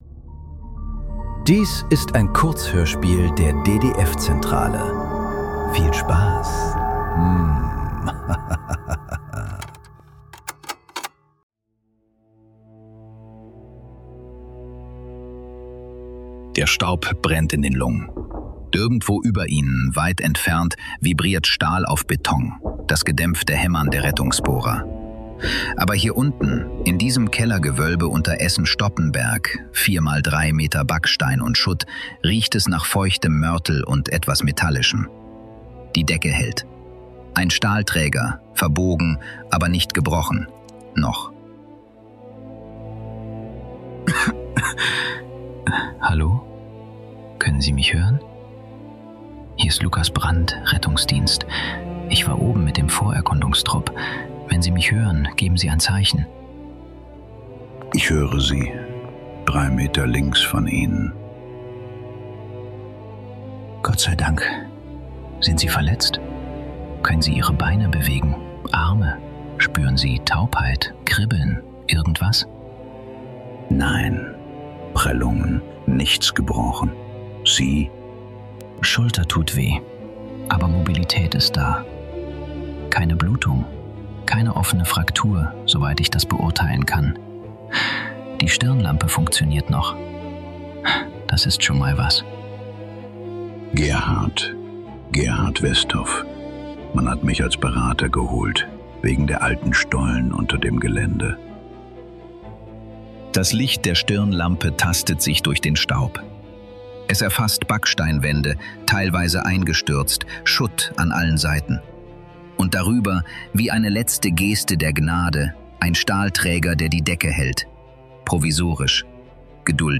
Kurzhörspiele. Leise.